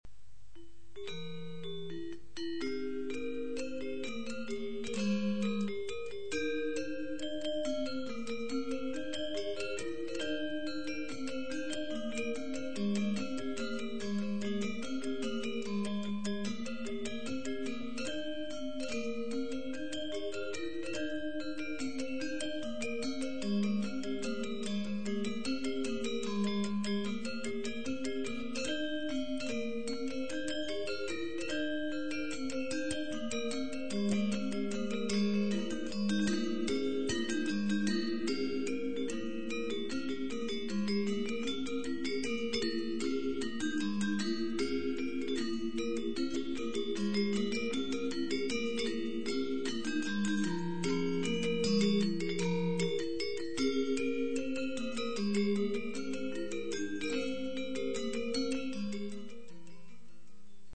青銅楽器の３人演奏
バリ島のホテルに到着すると青銅器の楽器で歓迎の音楽を奏でてくれる。
音は繊細で光と水が飛びはねるようである。心の襞にも入り込んでくるし、ウワッと宙に流れる音楽のようでもある。